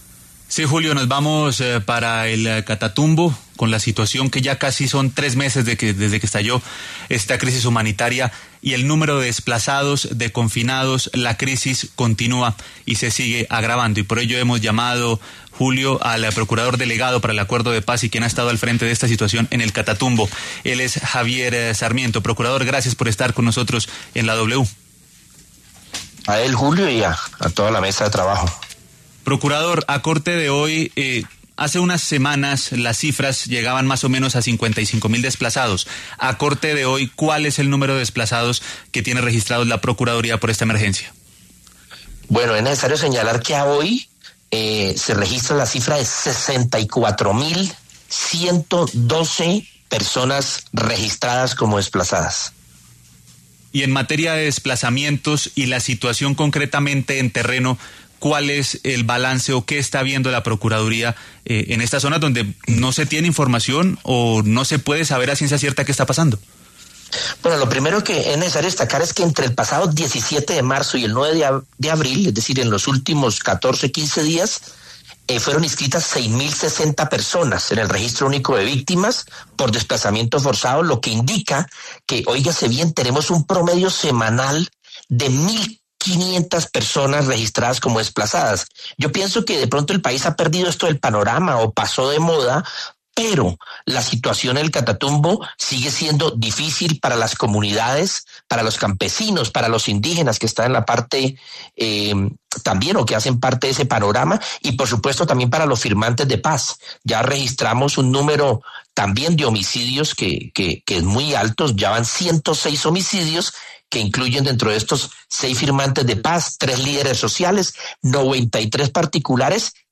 En entrevista con La W, el procurador delegado para el Acuerdo de Paz y quien ha estado al frente de la situación en el Catatumbo, Javier Sarmiento, se refirió a la situación en esa zona del país cerca de tres meses después del estallido de la emergencia humanitaria, y reveló que el número de desplazados supera las 64.000 personas.